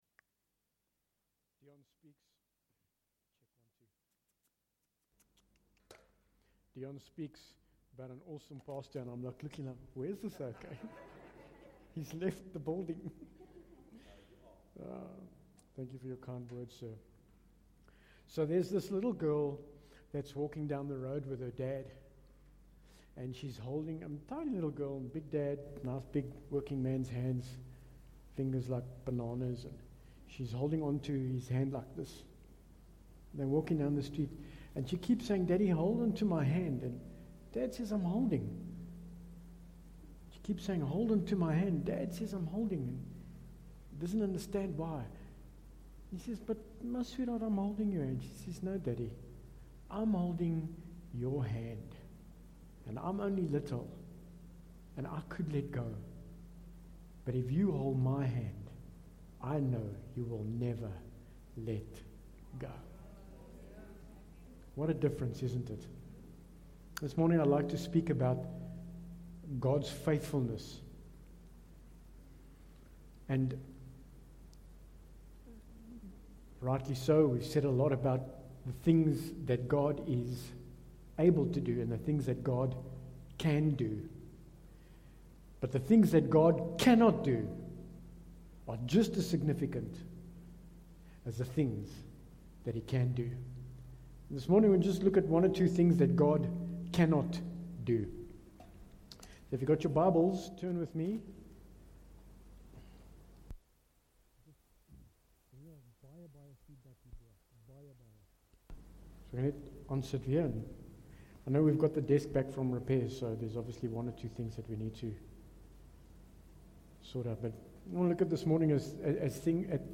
Bible Text: Titus 1:2 | Preacher